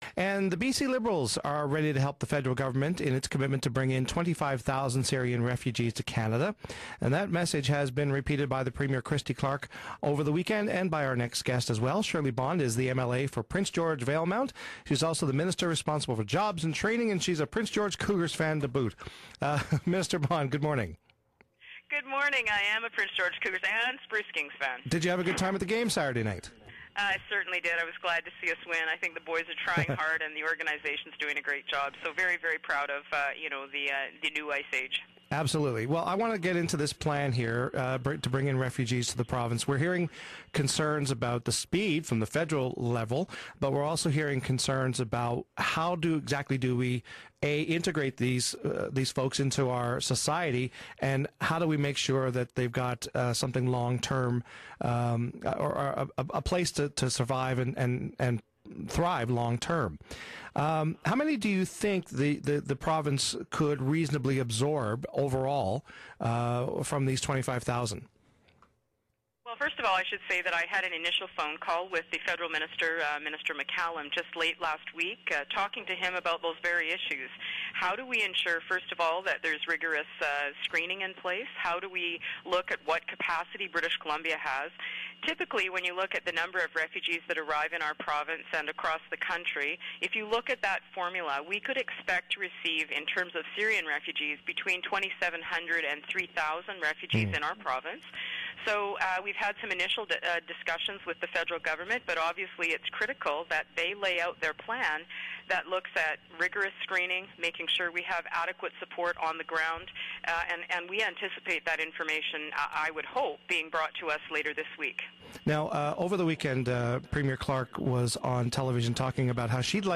Up to 3,000 Syrian refugees could be coming to B.C. by the end of the year, and the B.C, Liberals have suggested at least some should head north. We speak to MLA Shirley Bond about the province's strategy.